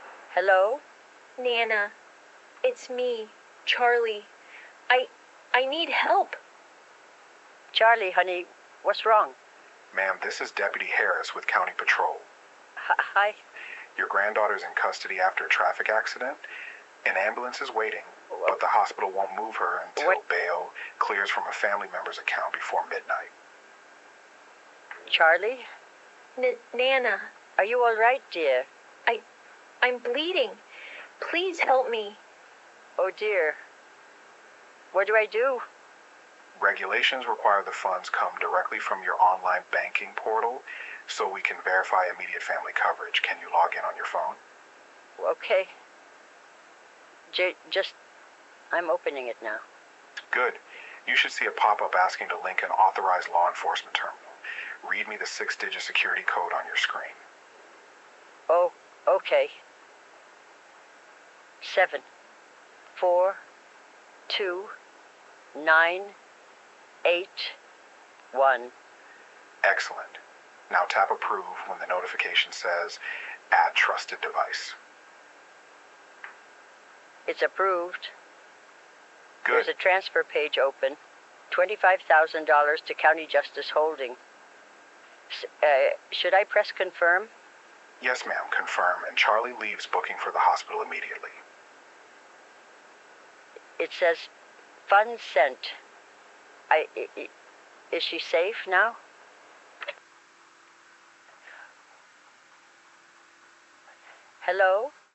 Hear a voice cloning scam in action
Now, with just a few seconds of audio, AI can generate longer, highly convincing messages in the target’s own voice.